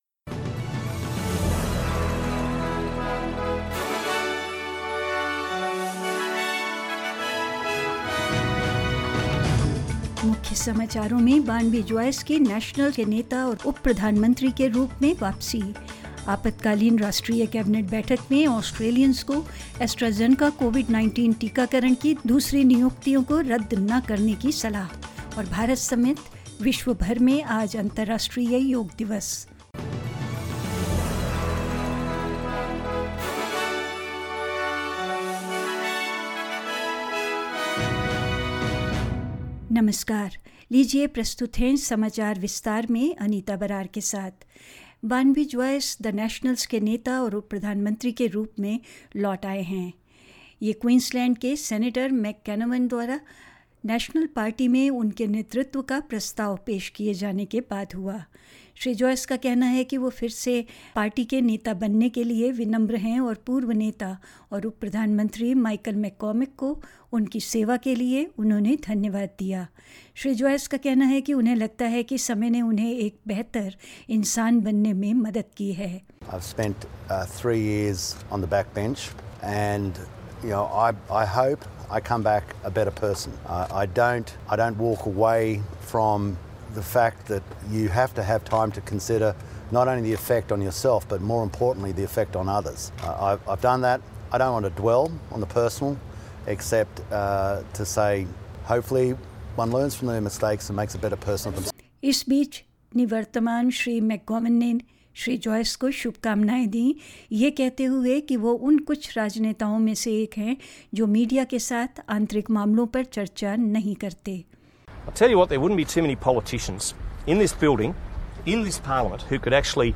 In this latest SBS Hindi News bulletin of Australia and India: Barnaby Joyce returns as leader of The Nationals and Deputy Prime Minister; An emergency National Cabinet meeting has been held, with Australians told not to cancel second appointments of AstraZeneca COVID-19 vaccinations; India records lowest single day new coronavirus infections in 81 days and more news.